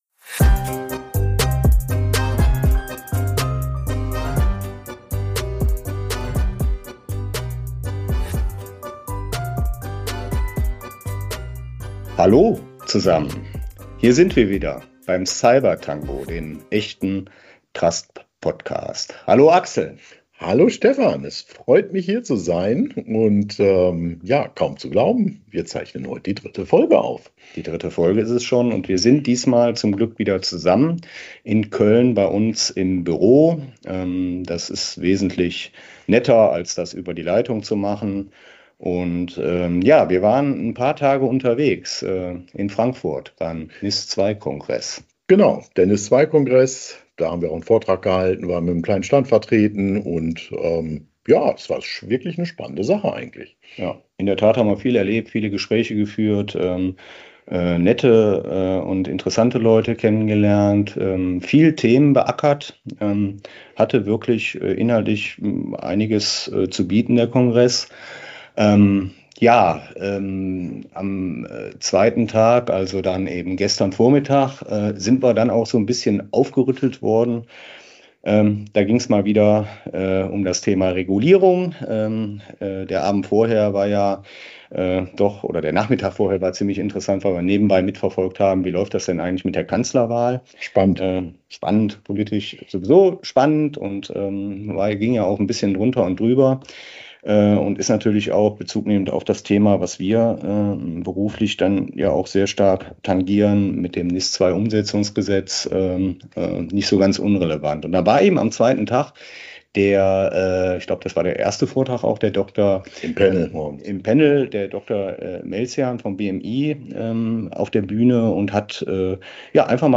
Beschreibung vor 11 Monaten Zwei Kollegen, eine Meinung: Es muss schneller gehen! Sie haben genug vom Schneckentempo der Gesetzgebung zum NIS-2 Umsetzungsgesetz.
Unterschiedliche Perspektiven, klare Worte – und ein bisschen Humor.